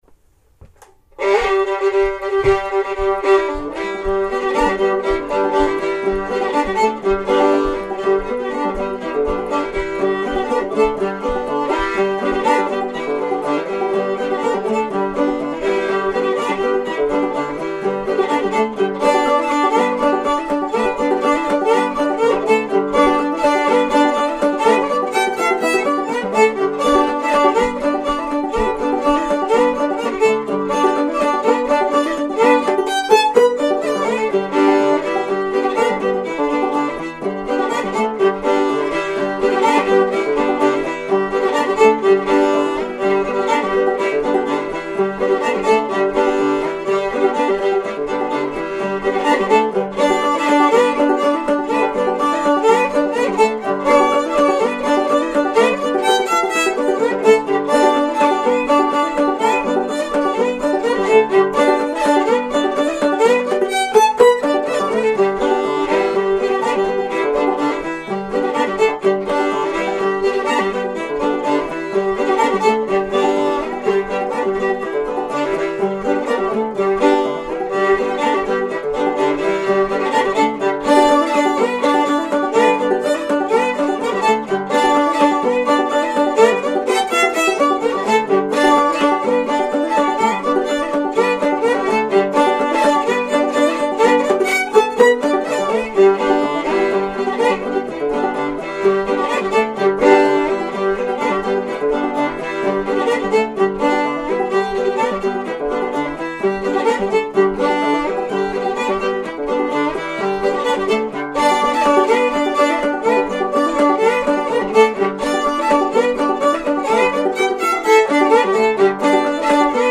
fiddle
banjo
Music: Traditional North Carolina fiddle tune